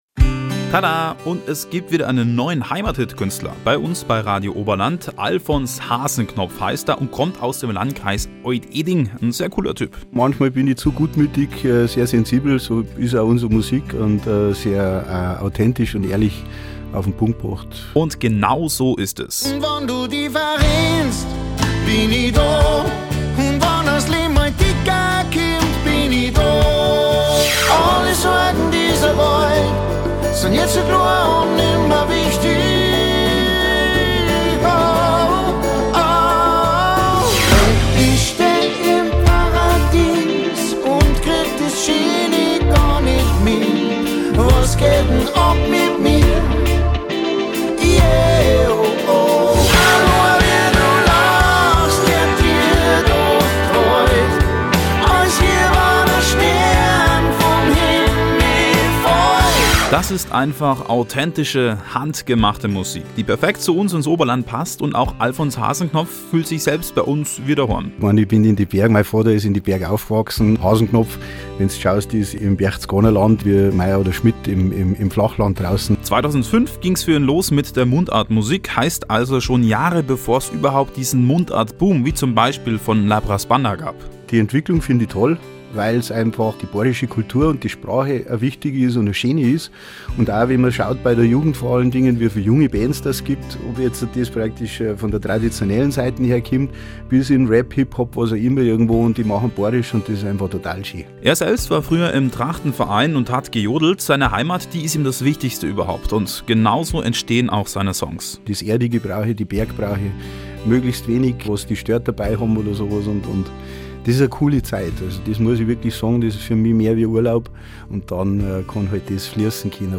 Hier ein Ausschnitt vom Interview zum neuen Album Hand in Hand.